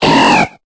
Cri de Barloche dans Pokémon Épée et Bouclier.